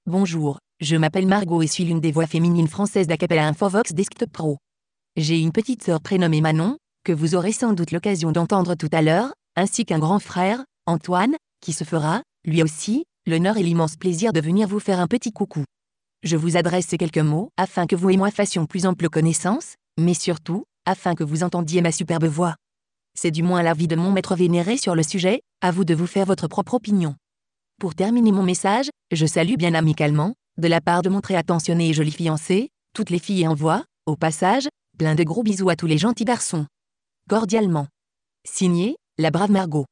Texte de démonstration lu par Margot, voix féminine française d'Acapela Infovox Desktop Pro
Écouter la démonstration de Margot, voix féminine française d'Acapela Infovox Desktop Pro